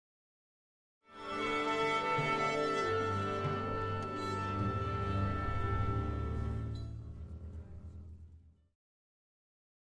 Orchestra
Orchestra Tuning Ambience 2 - Strings Mainly Long Symphonic - Musical